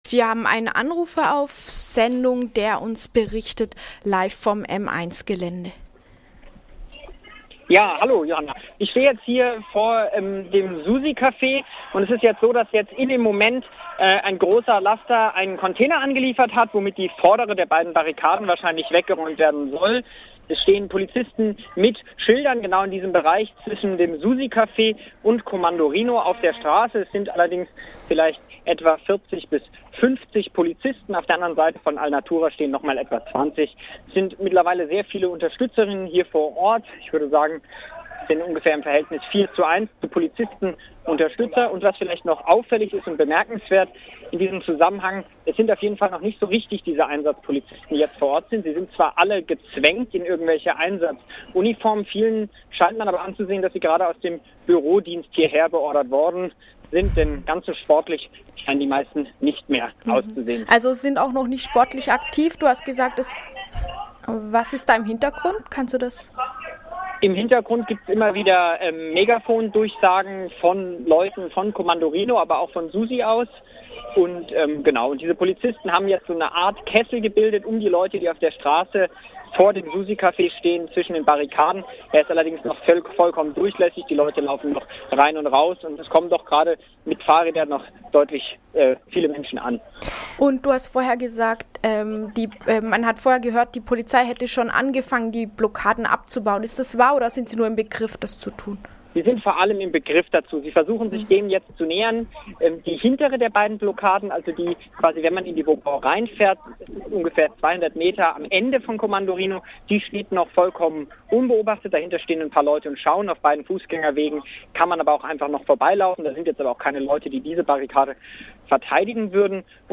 3. Bericht vom M 1 (13:10 Uhr) Um 13.10 Uhr der dritte Zwischenbericht zum Stand